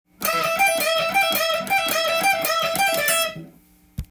使いやすいようにAmペンタトニックスケールで譜面にしてみました。
そして、エレキギター特有のチョーキングを多用しているのも